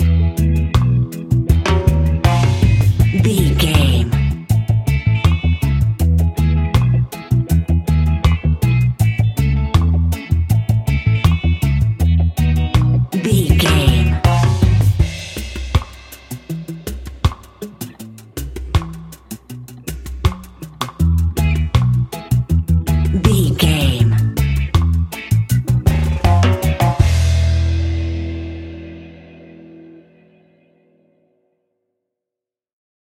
Classic reggae music with that skank bounce reggae feeling.
Ionian/Major
dub
instrumentals
laid back
chilled
off beat
drums
skank guitar
hammond organ
transistor guitar
percussion
horns